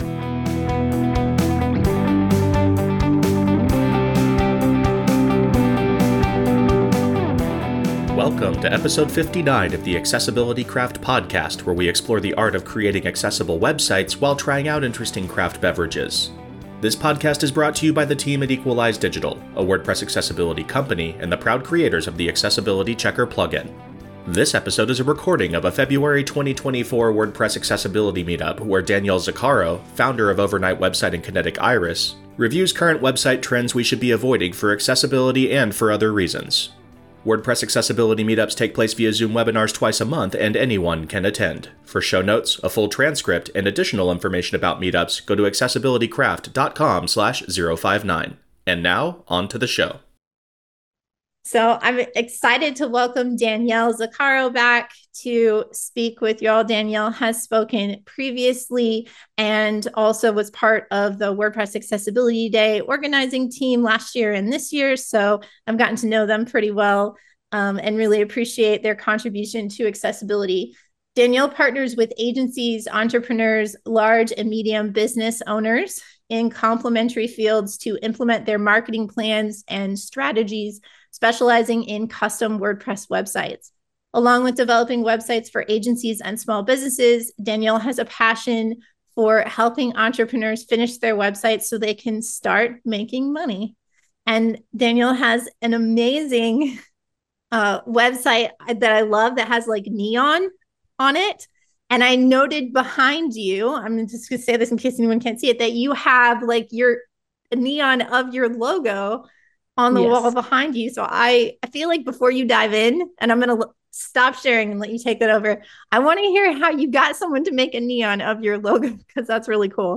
This episode is a recording of a February 2024 WordPress Accessibility Meetup.